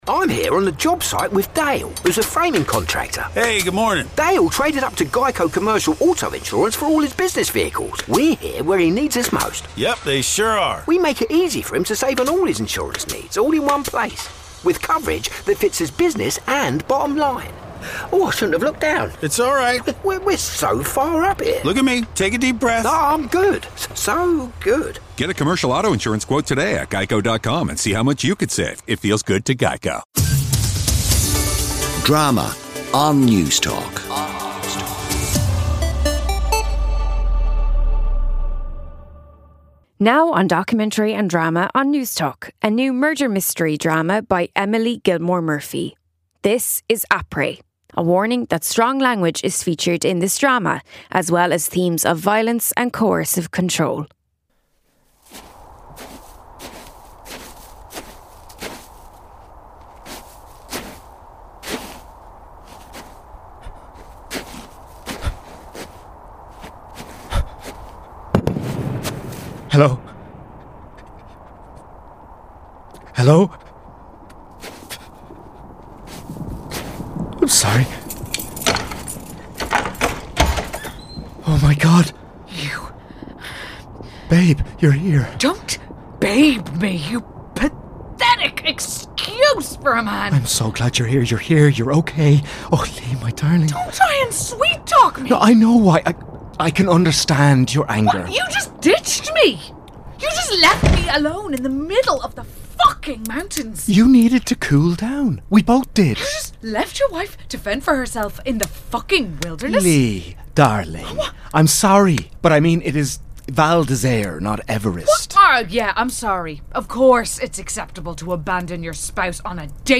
Series celebrating the best in home-grown radio drama, from some of Ireland's finest radio producers.